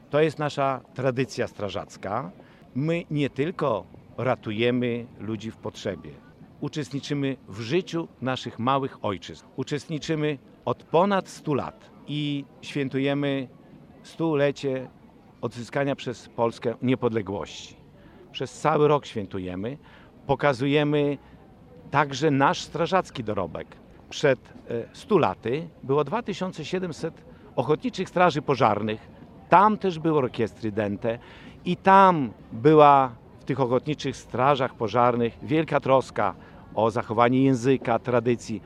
XXV Regionalny Przegląd Orkiestr Ochotniczych Straży Pożarnych odbył się w niedzielę (17.06) w Suwałkach.